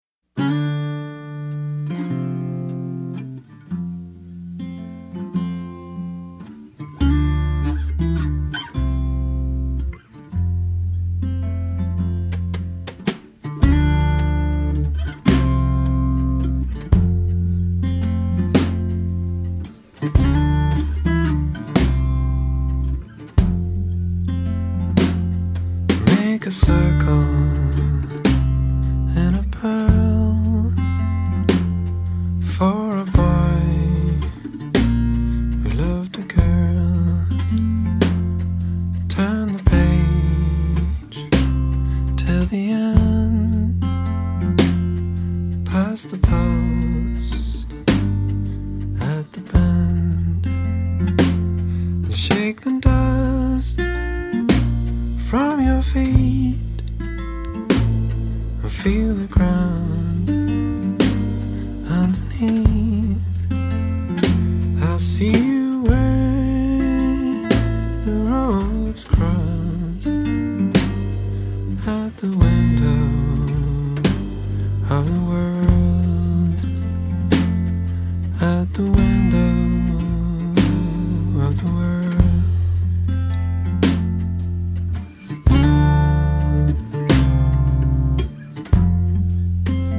另類搖滾樂風與世界音樂的混搭，使用各種民族樂器，包括自己演奏二胡，都替音樂增加了更多層次的色彩。
人聲與各個樂器定位精準、並且有極佳的協調性，可以說是一張不可多得的男聲演唱專輯。